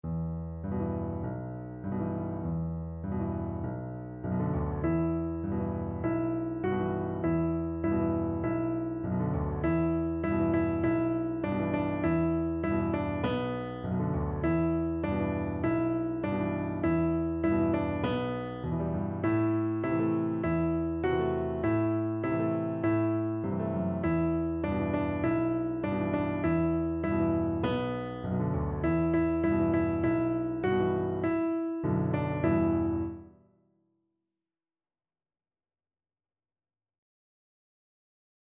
Free Sheet music for Piano Four Hands (Piano Duet)
4/4 (View more 4/4 Music)
Moderato
C major (Sounding Pitch) (View more C major Music for Piano Duet )